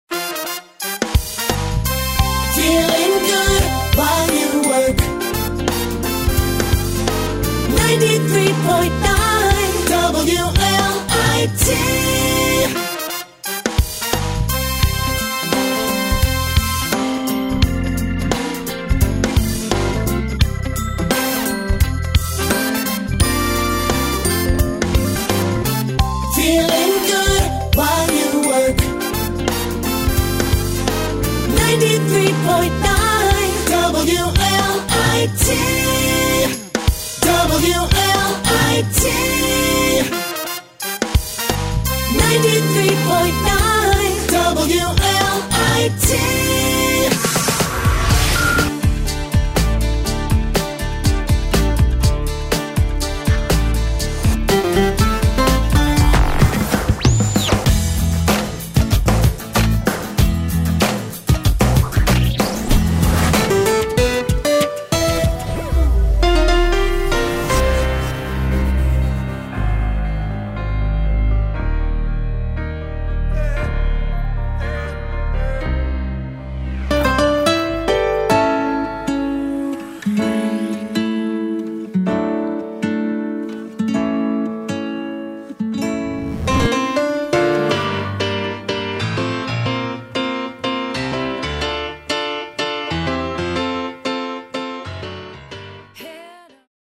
is a feel good, organ theme
new Logo Segues for use between songs